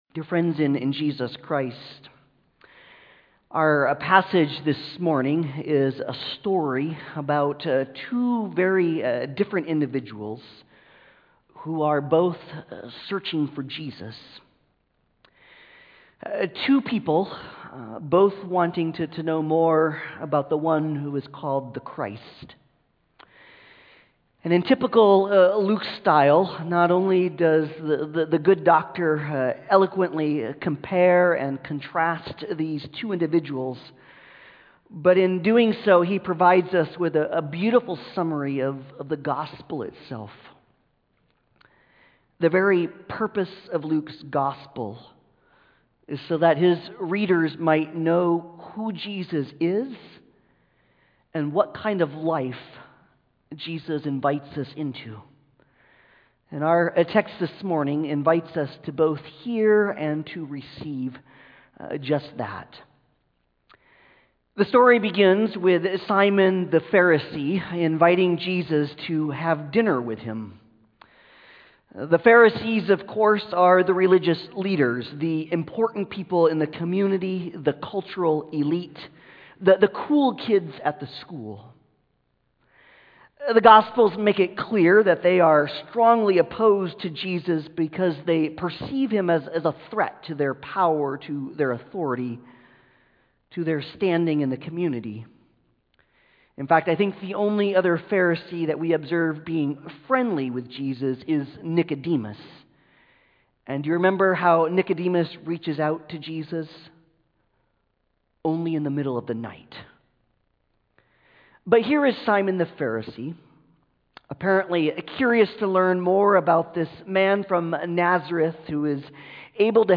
Passage: Luke 7:36-50 Service Type: Sunday Service